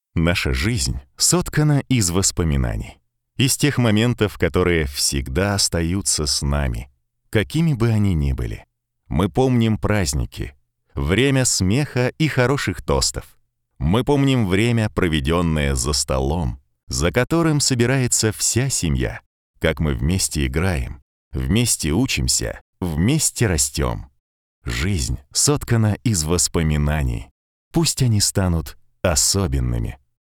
Тракт: Микрофон Neumann TLM103, Ламповый предусилитель/компрессор - Presonus ADL700.
Акустически обработанная панелями voice-booth ("микрофонка").
Демо-запись №3 Скачать